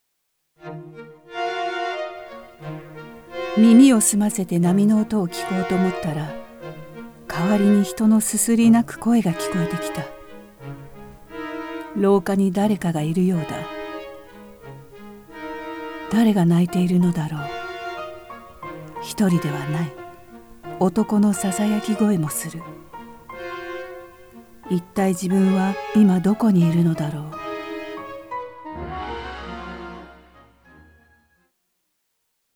朗読
ボイスサンプル